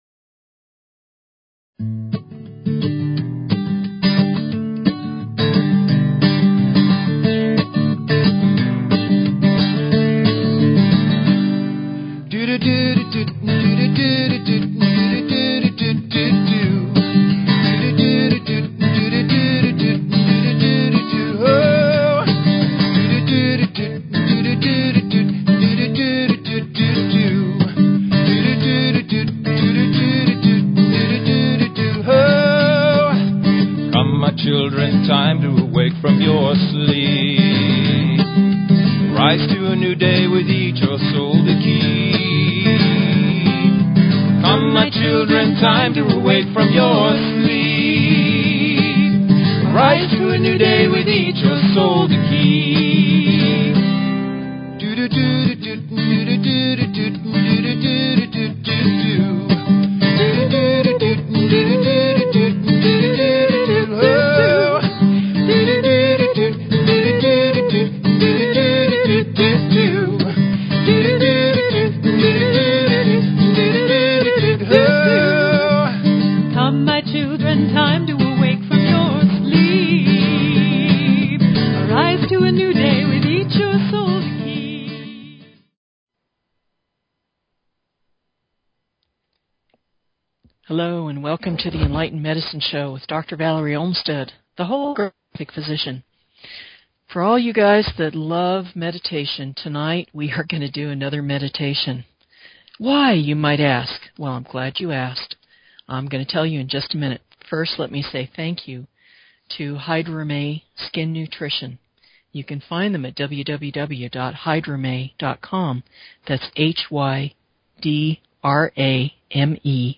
Talk Show Episode, Audio Podcast, Enlightened_Medicine and Courtesy of BBS Radio on , show guests , about , categorized as
Tune in for the discussion; the meditation will be in the last 30 minutes.